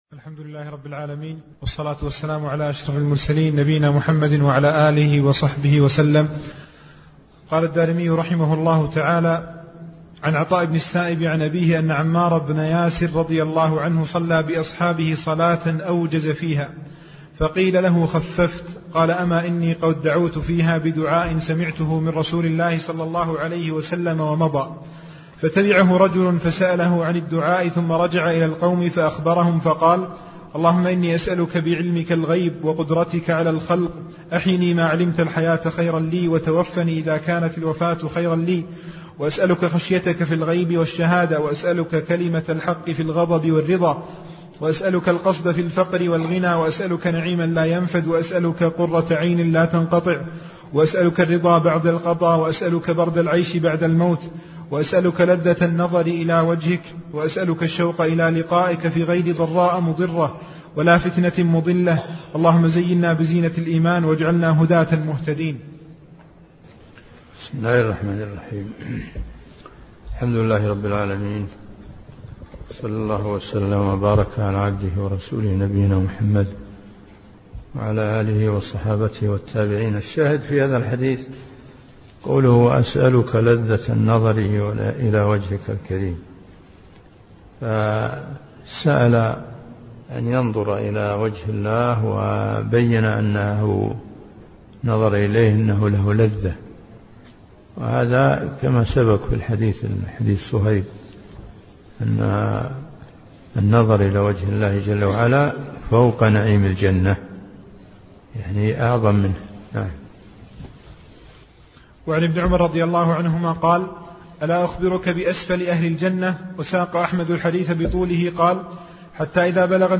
تفاصيل المادة عنوان المادة الدرس (6) شرح رسالة في الصفات تاريخ التحميل الخميس 9 فبراير 2023 مـ حجم المادة 29.54 ميجا بايت عدد الزيارات 175 زيارة عدد مرات الحفظ 64 مرة إستماع المادة حفظ المادة اضف تعليقك أرسل لصديق